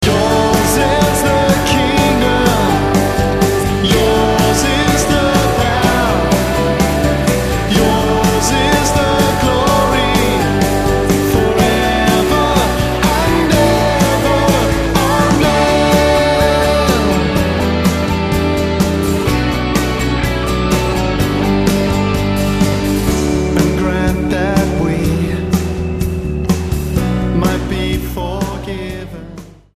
STYLE: Pop
with its nagging guitar riff